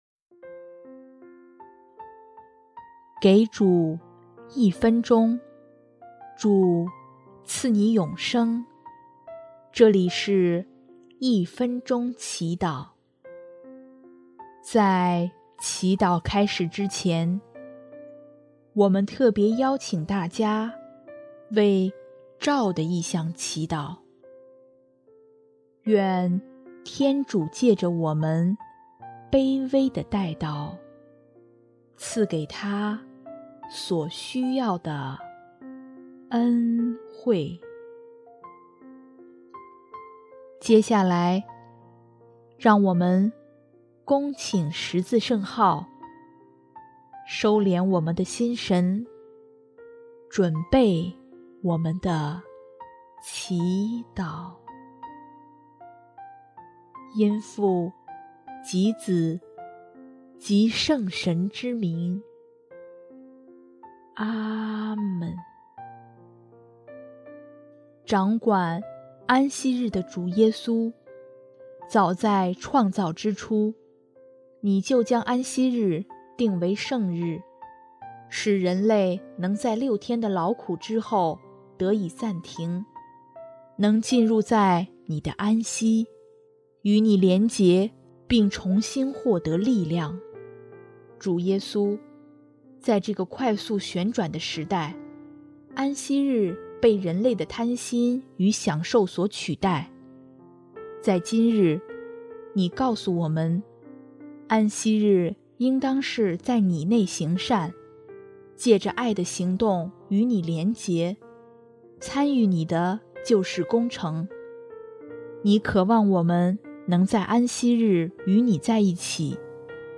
音乐： 主日赞歌《我要使你们安息》